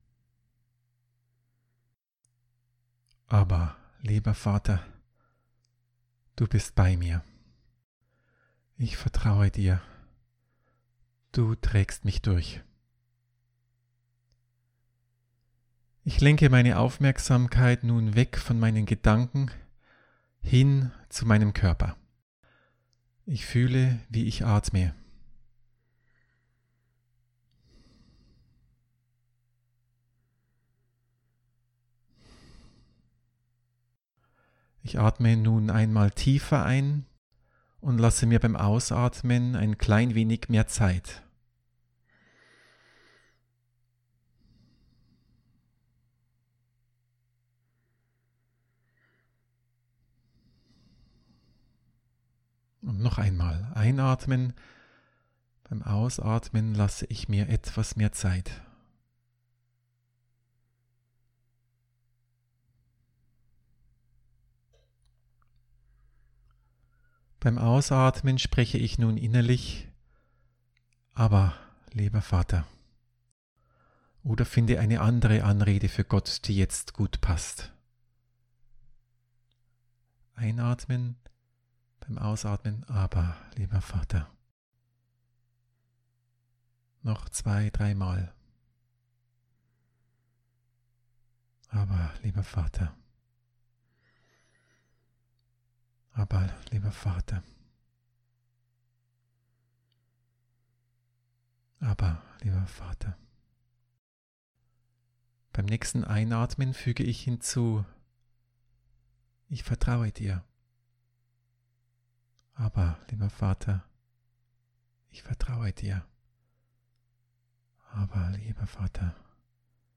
Audio Anleitung